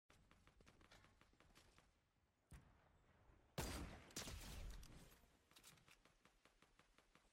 OG Fortnite Easy Sniper sound effects free download